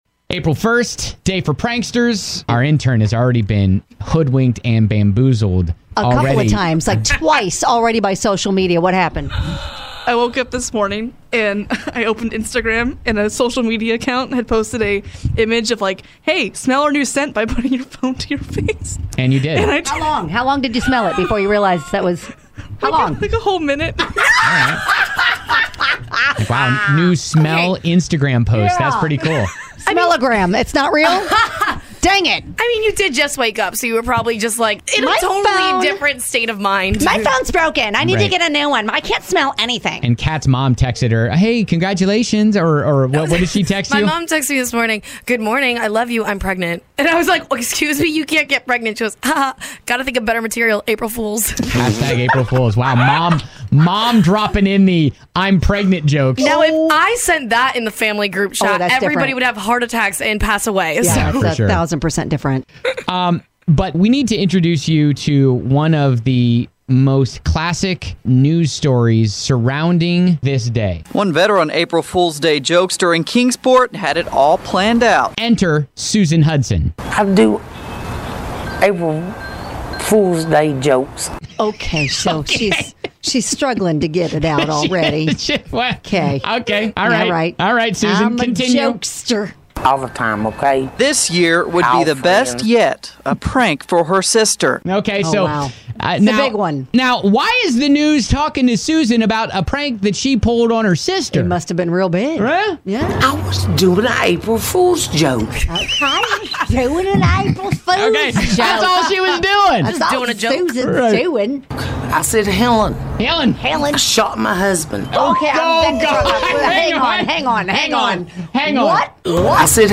This time, she learned a lesson in this classic news segment.